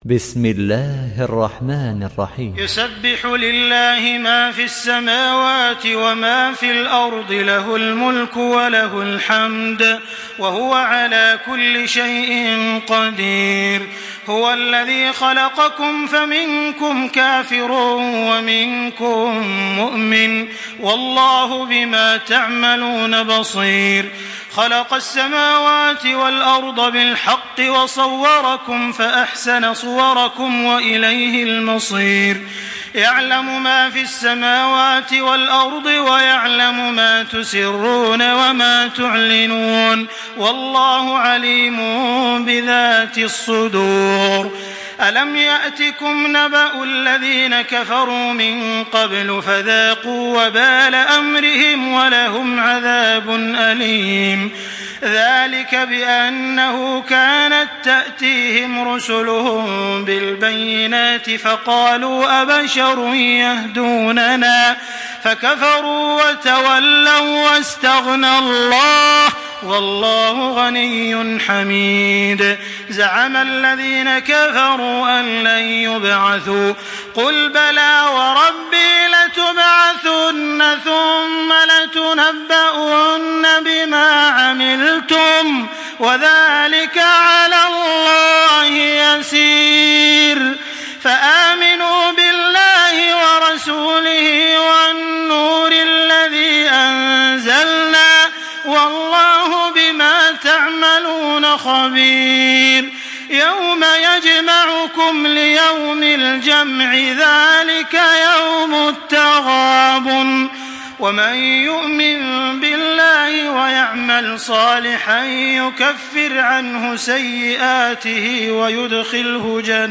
Surah At-Taghabun MP3 by Makkah Taraweeh 1425 in Hafs An Asim narration.
Murattal Hafs An Asim